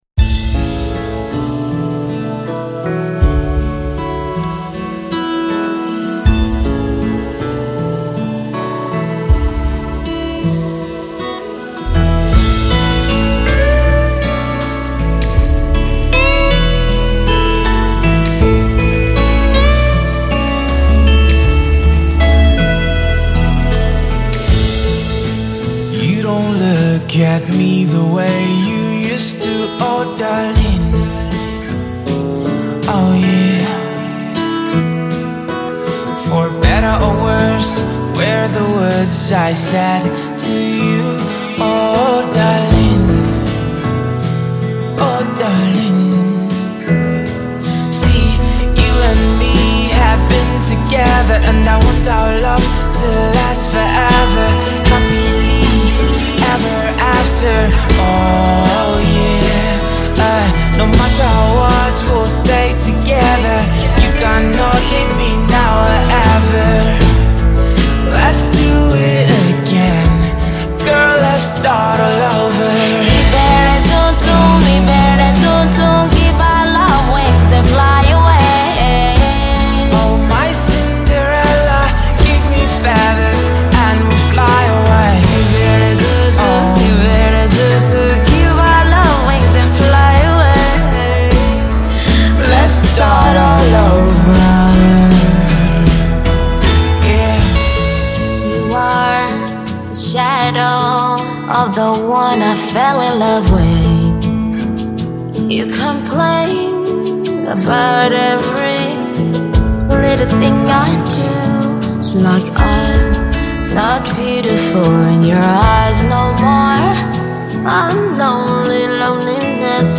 beautiful duet